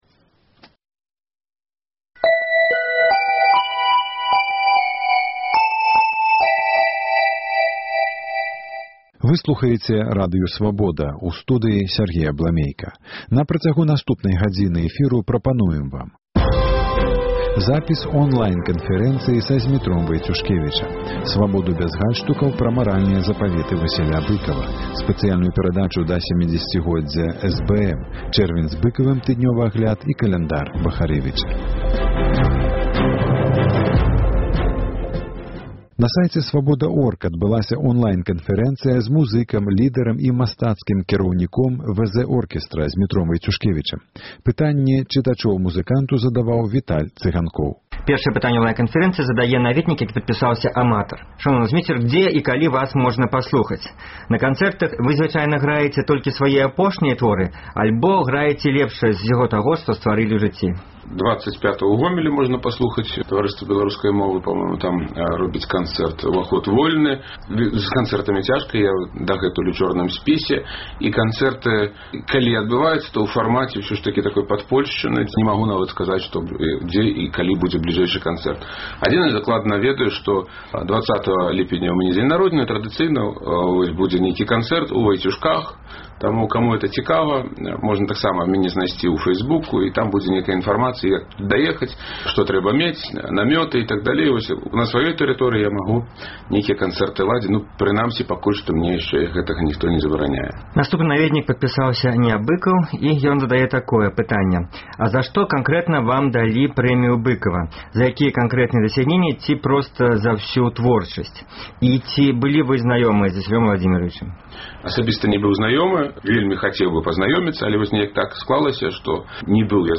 Онлайн-канфэрэнцыя з музыкам, лідэрам і мастацкім кіраўніком WZ-Orkiestra Зьмітром Вайцюшкевічам.